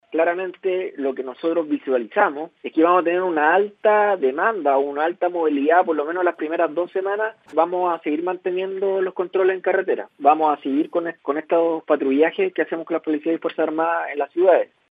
El seremi de salud, Francisco Álvarez, señaló que en las próximas dos semanas podría notarse incluso un aumento significativo en la movilidad, pero insistió en que se incrementarán las fiscalizaciones.